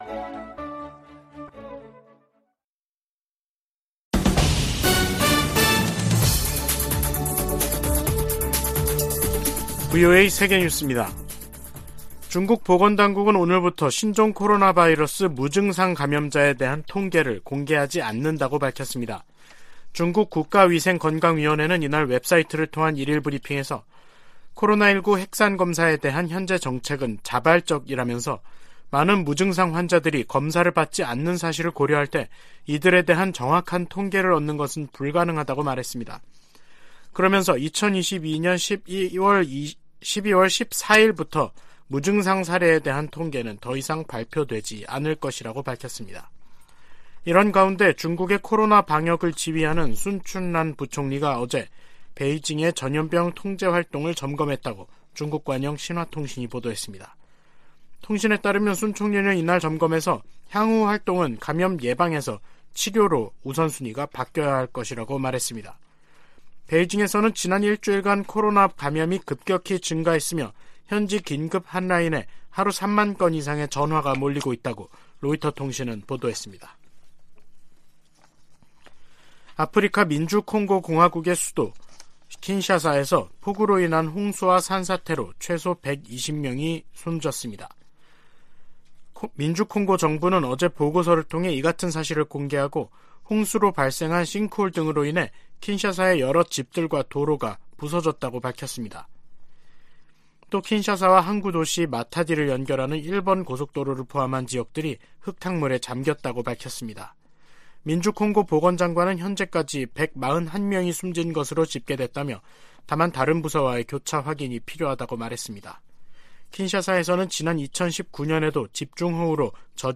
VOA 한국어 간판 뉴스 프로그램 '뉴스 투데이', 2022년 12월 14일 3부 방송입니다. 미국 국무부가 유럽연합(EU)의 대북 추가 독자제재 조치를 높이 평가하면서 북한 정권에 책임을 물리기 위해 동맹, 파트너와 협력하고 있다고 밝혔습니다. 북한의 인도주의 위기는 국제사회의 제재 때문이 아니라 김정은 정권의 잘못된 정책에서 비롯됐다고 유엔 안보리 대북제재위원장이 지적했습니다.